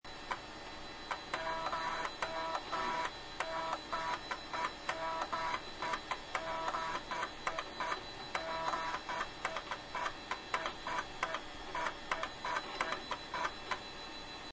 Disketten
Der Sound war unvergleichlich und wurde nur vom Modem getoppt.